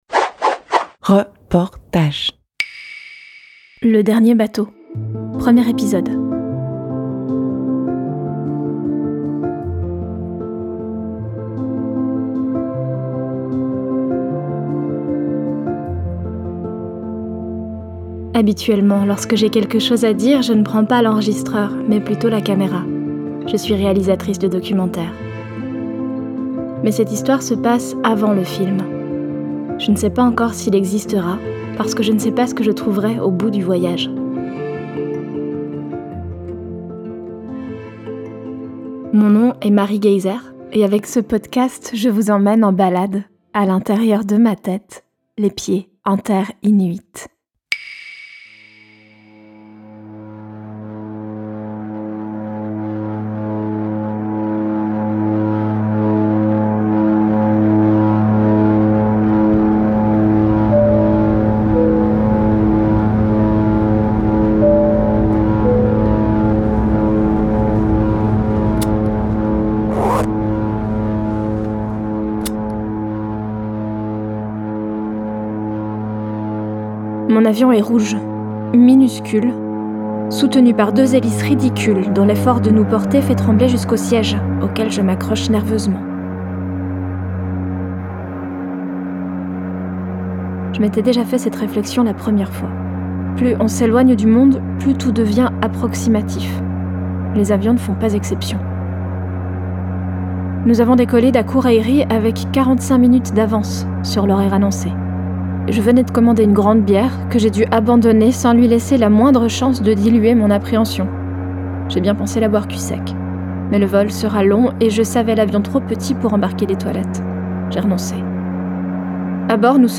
Série documentaire